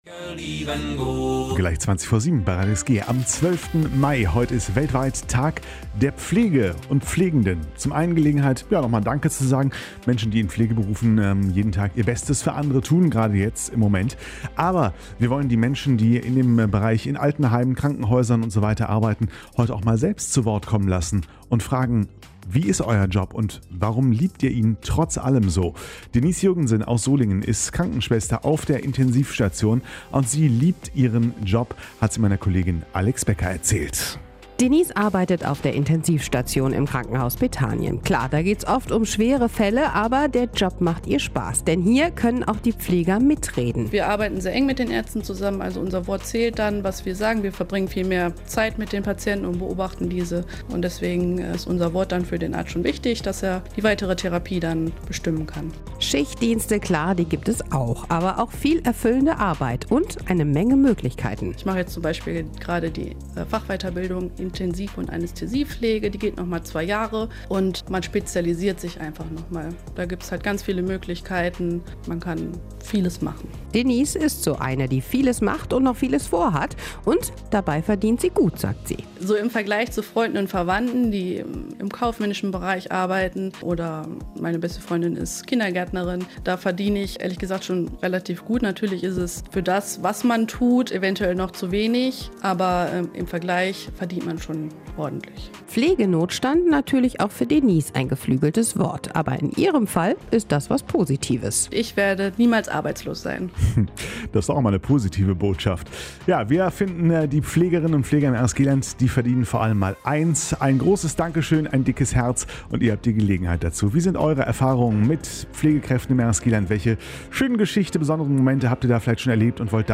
Zum internationalen Tag der Pflege (12. Mai) haben uns Pflegekräfte aus dem RSG-Land aus ihrem Arbeitsalltag erzählt.